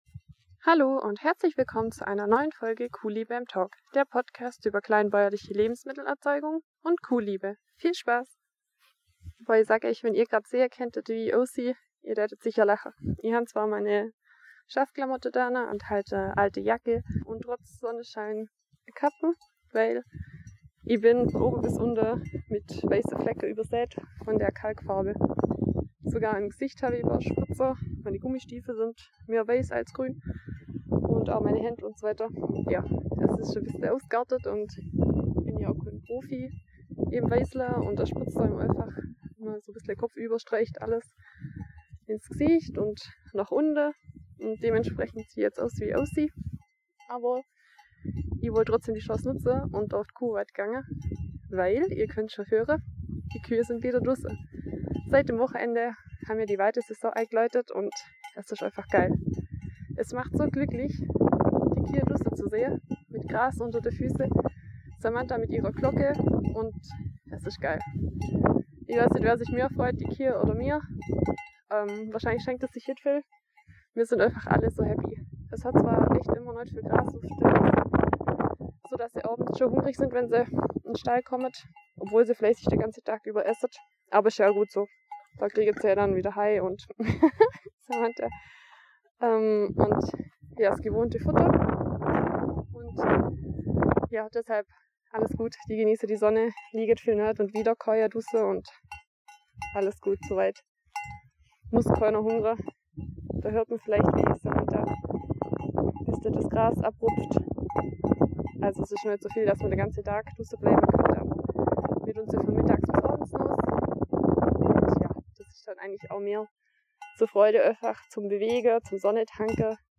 Leider hat mir der Wind einen Strich durch die Rechnung gemacht bei der Podcastaufzeichnung auf der Kuhweide mit dem altbekannten Kuhglockengeläute, indem er störende Hintergrundgeräusche verursacht hat. (Stellt den Ton lieber nicht zu laut ein.)